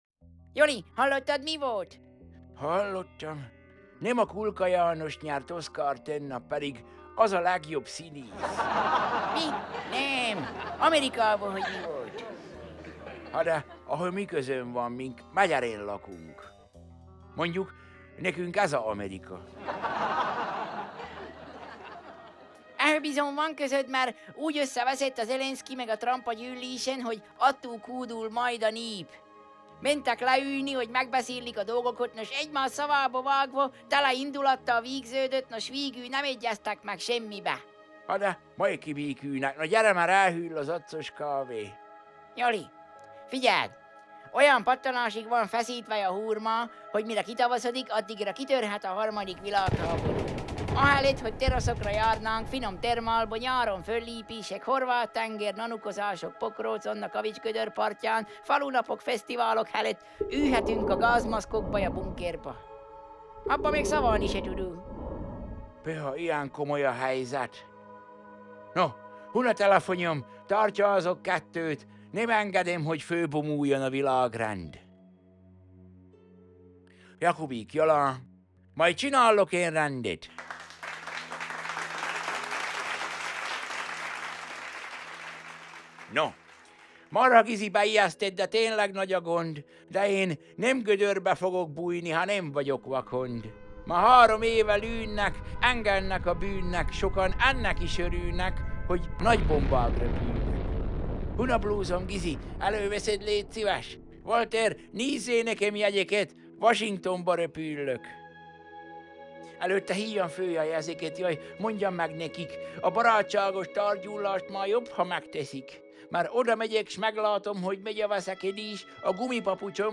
Szaval a jó öreg csallóközi
Zene: